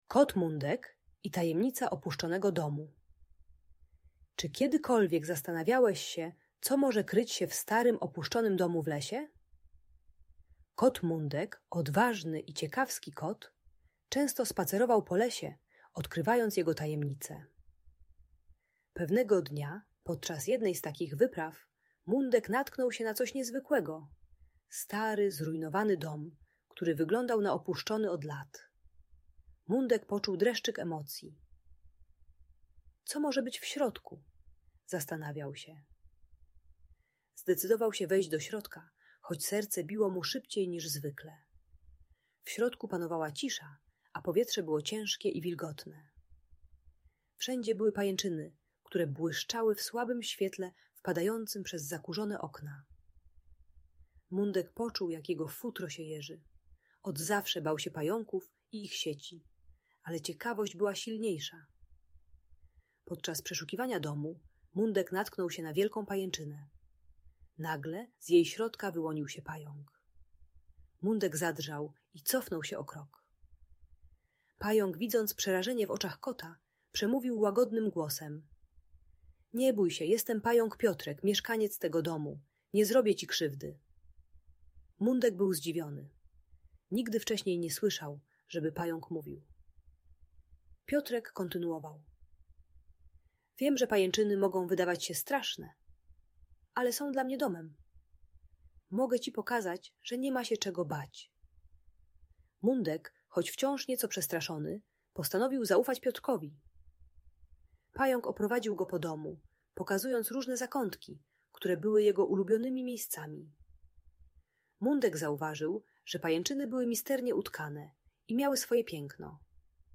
Kot Mundek i Tajemnica Opuszczonego Domu - Lęk wycofanie | Audiobajka